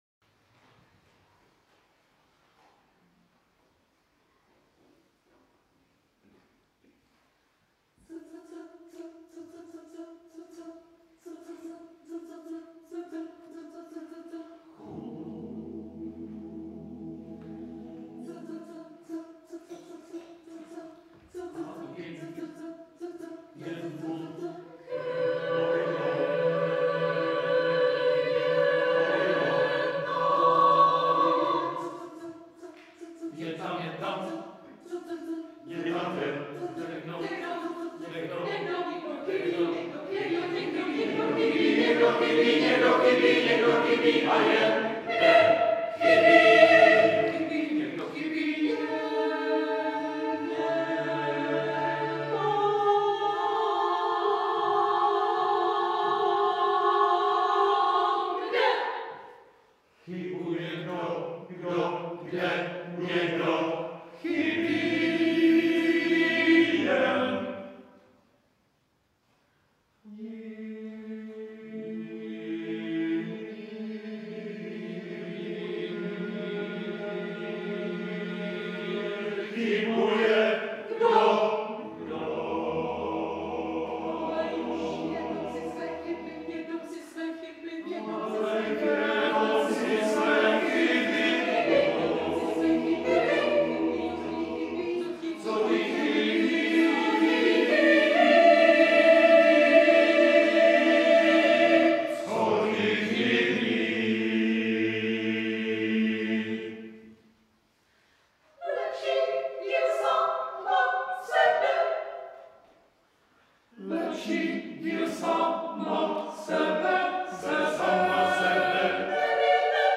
pro osmihlasý smíšený sbor a cappella